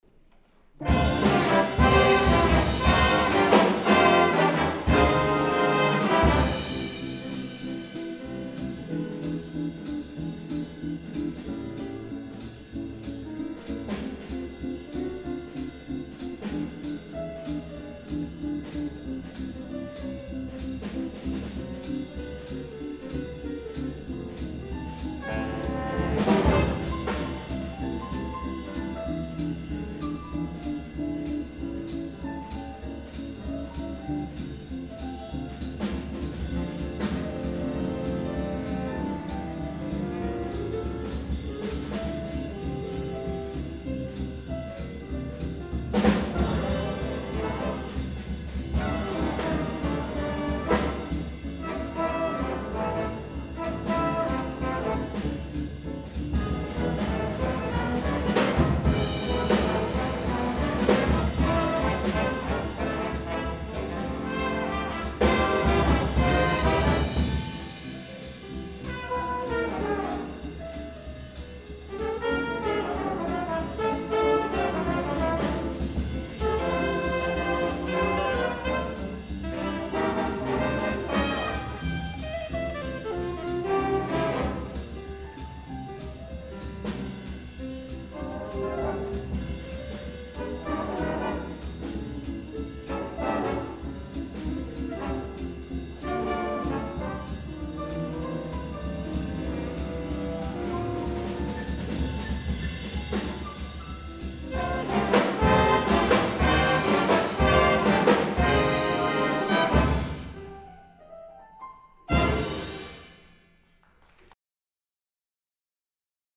Audio recording of East Carolina University Jazz Ensemble B - ECU Digital Collections